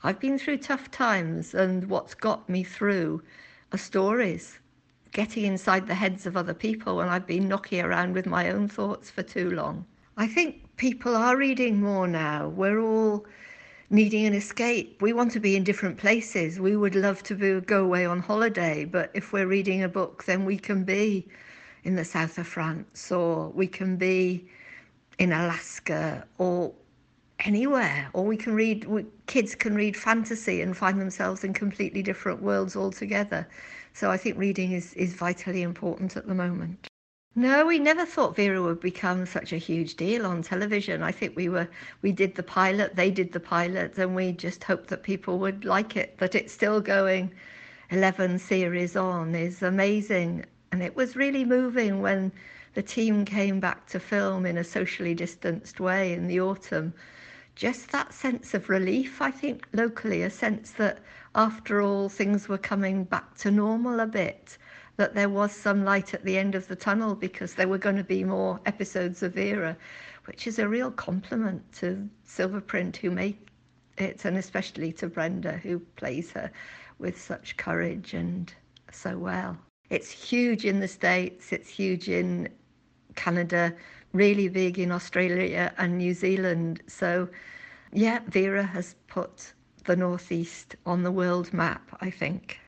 BELOW: Listen to Ann Cleeves talk about the value of books, and about the success of her now-famous TV detective, Vera Stanhope.